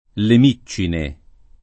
vai all'elenco alfabetico delle voci ingrandisci il carattere 100% rimpicciolisci il carattere stampa invia tramite posta elettronica codividi su Facebook Miccine , le [ le m &©© ine ] (ant. le Micciole [ le m &©© ole ]) top.